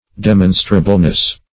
Search Result for " demonstrableness" : The Collaborative International Dictionary of English v.0.48: Demonstrableness \De*mon"stra*ble*ness\, n. The quality of being demonstrable; demonstrability.